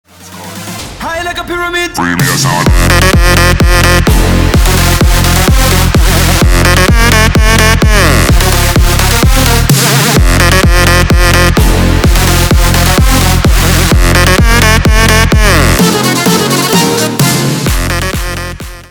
Electronica_7.mp3